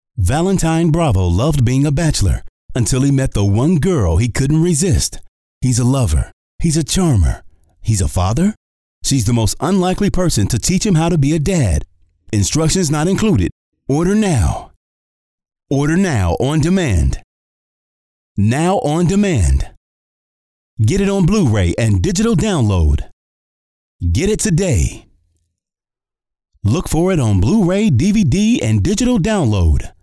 Smooth Bassy Voice Very articulate, Very Clear!
Sprechprobe: Sonstiges (Muttersprache):